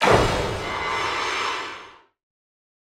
Processed Hits 09.wav